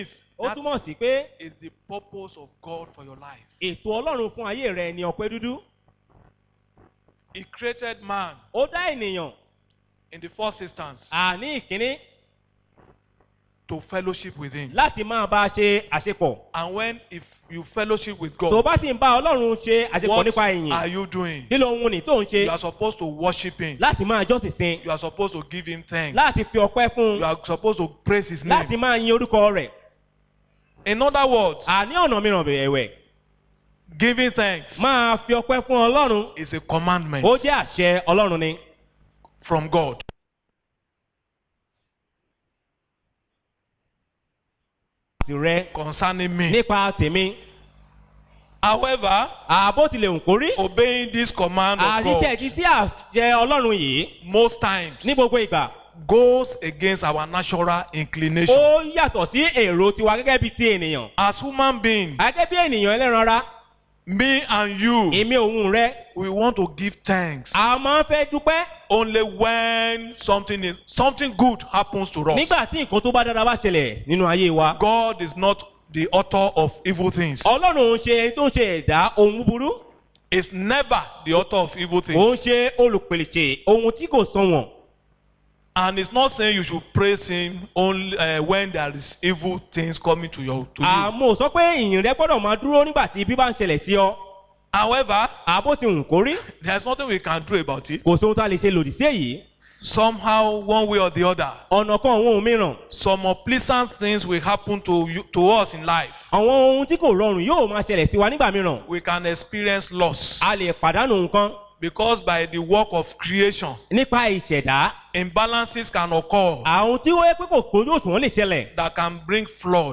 Posted in Thanksgiving Service